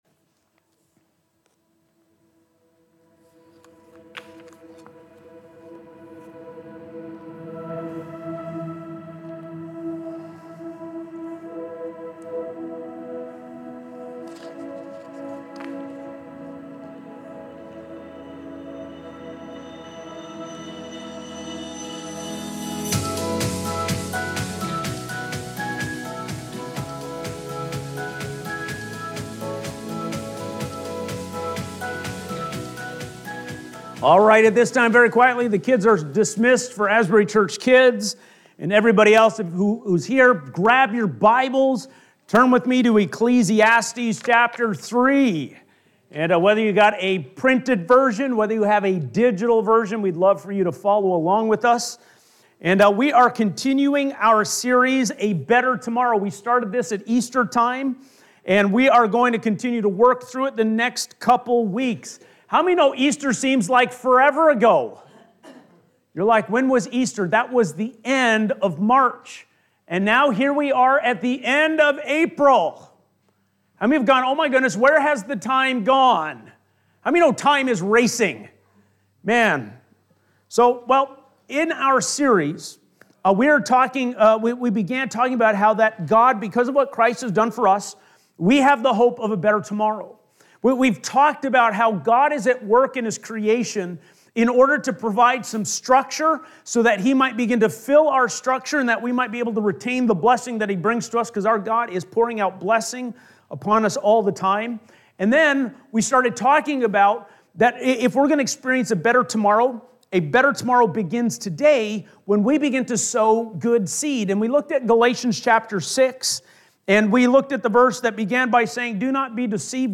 Changing-Time-Sermon.mp3